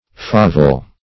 favel - definition of favel - synonyms, pronunciation, spelling from Free Dictionary
favel \fa"vel\ (f[=a]"v[e^]l), a. [OF. fauvel, favel, dim. of F.
favel.mp3